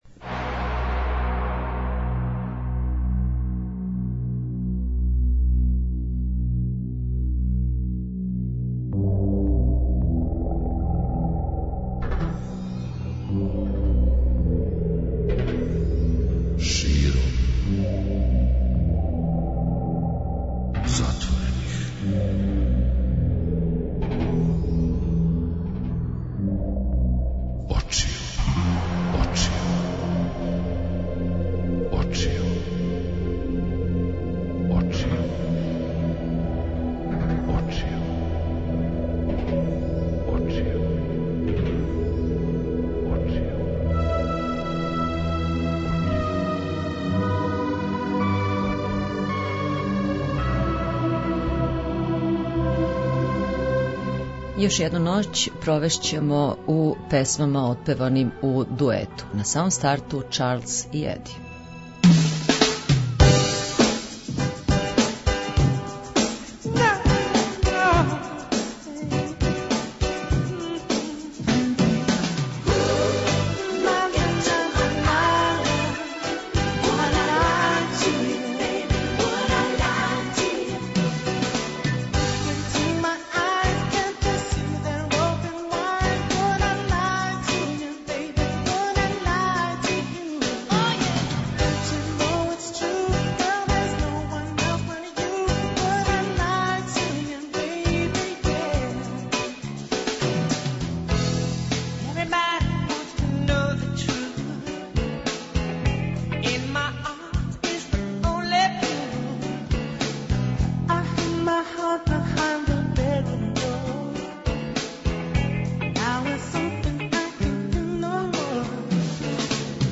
Како смо већ навикли, ноћ између понедељка и уторка посвећујемо доброј музици. И ове ноћи преслушаваћемо песме отпеване у дуету, и тако све до јутра.